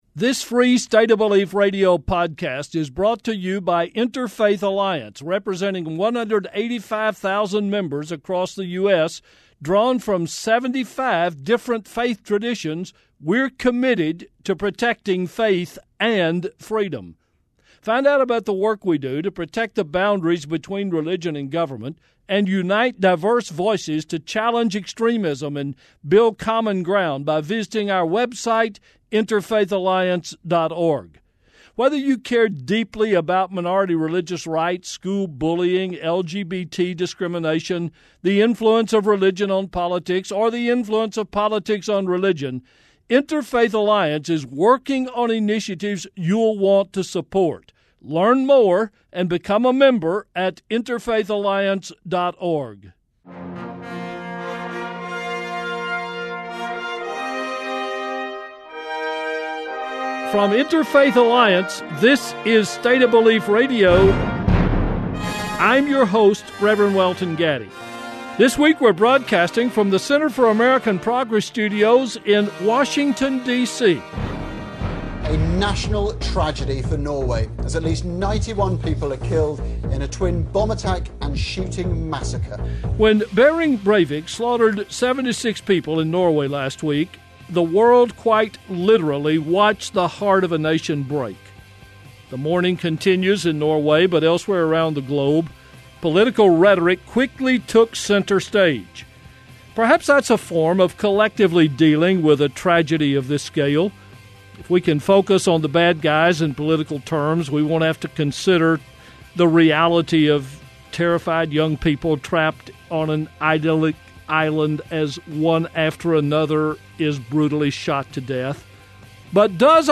Tune in this weekend to Interfaith Alliance’s weekly radio show and podcast State of Belief as we examine last week’s massacre in Norway and this week’s protest at the U.S. Capitol by a coalition of religious leaders demanding that budget decisions not exclude the poor and underprivileged.